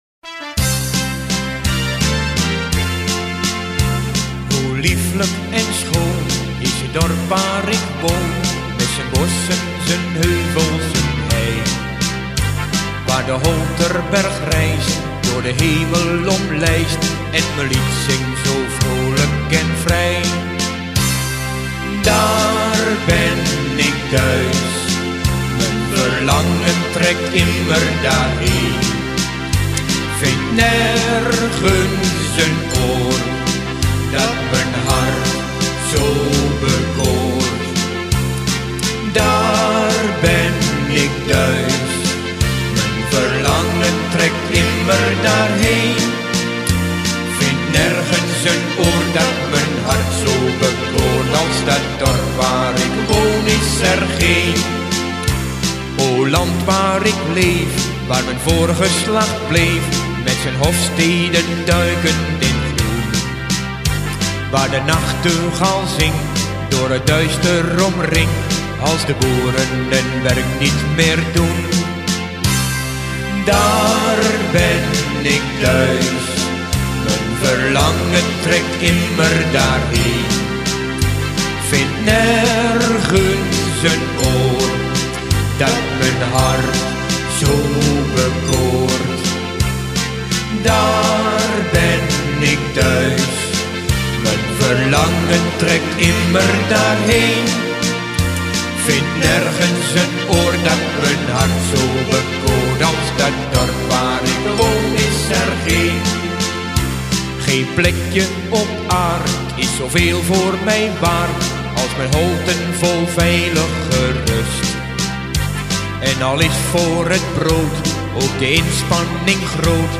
Nog een liedje over Holten gezongen door het Salland Combo.